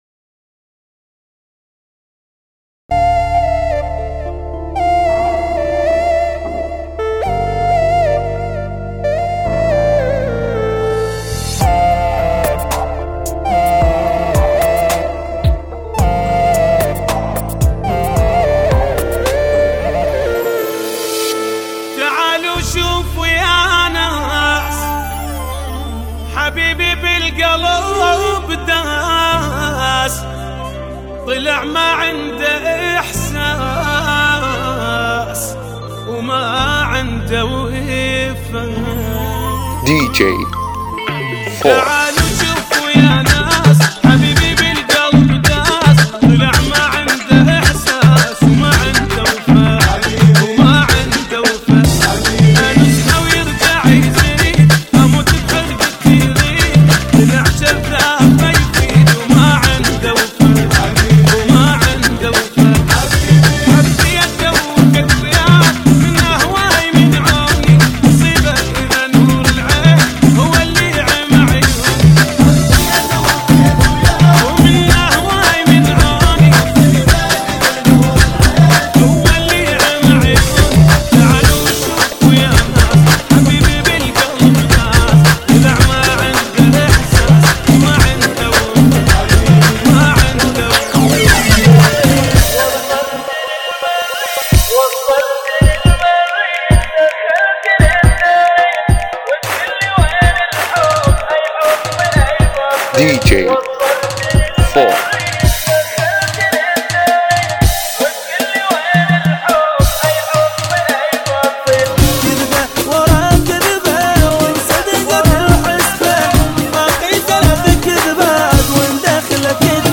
MiniMix